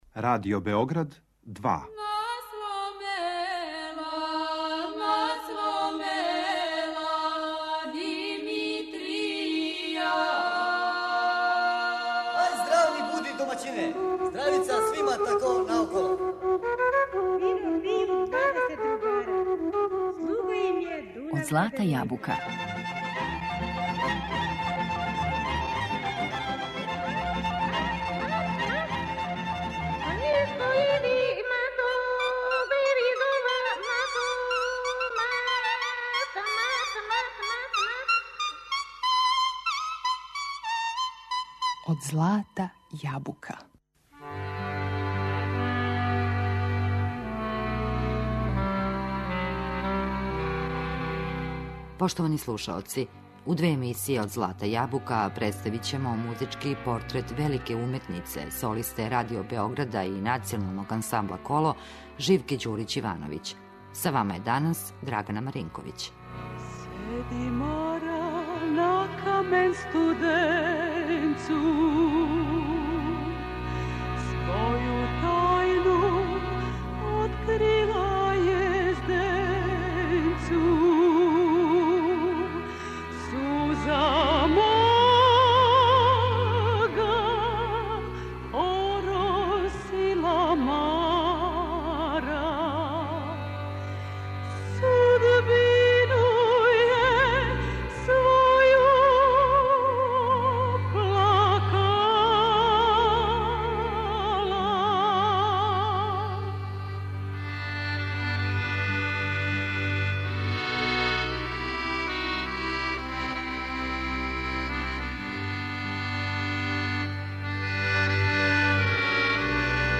Била је драгоцен извођач, јер је за тонски архив забележила велики број трајних снимака најлепших изворних народних и градских песама, као и бројне песме широких балканских простора.